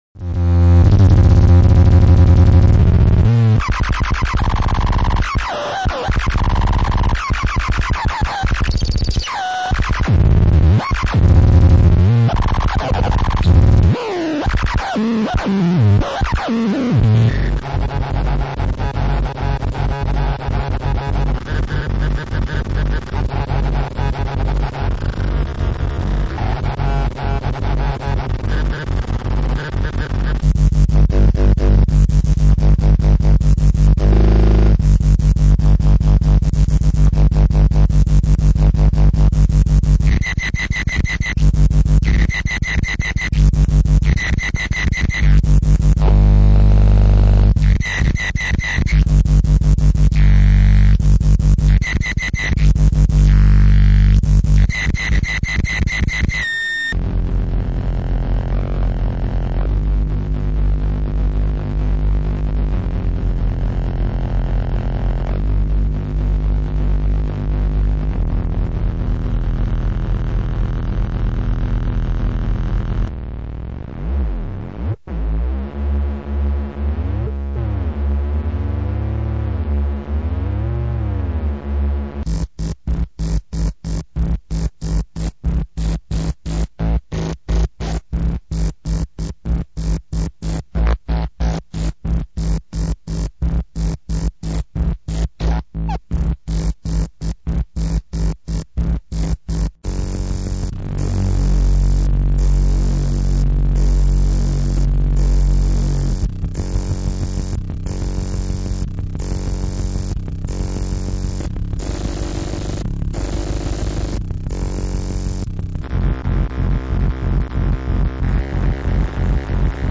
It's just minimalistic sound.